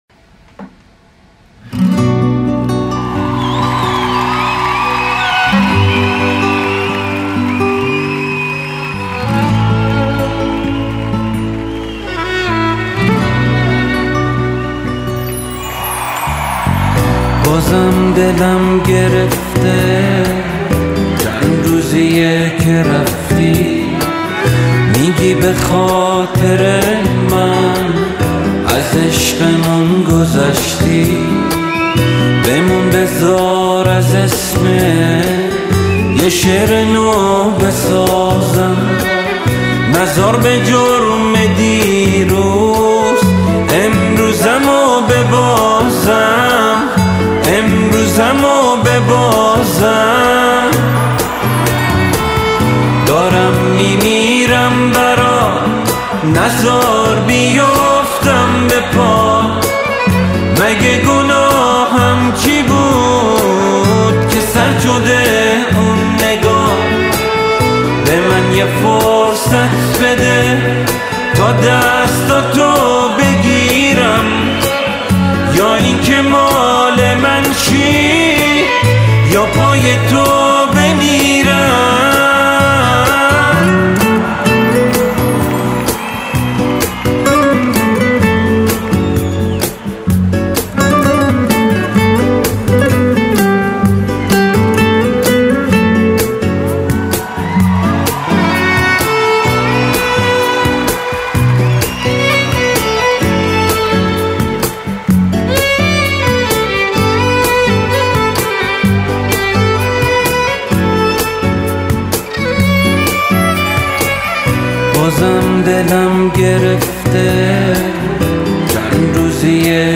(Live In Concert)